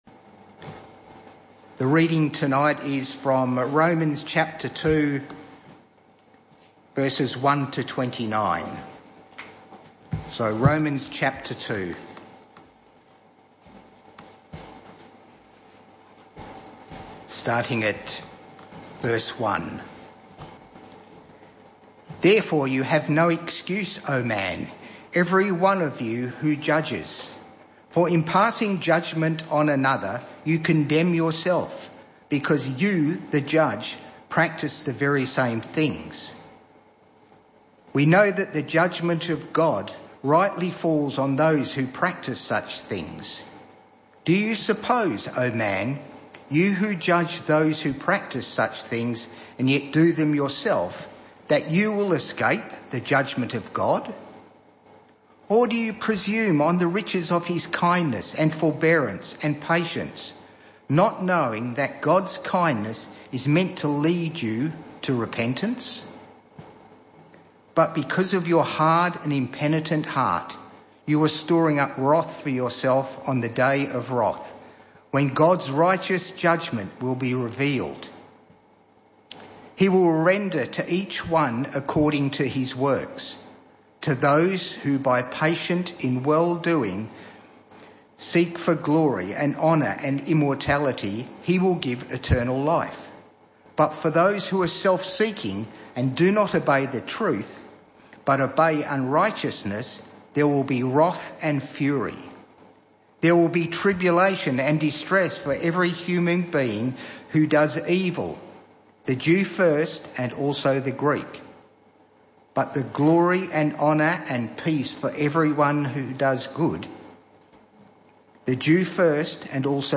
This talk was part of the PM Service series entitled The Heart Of The Gospel.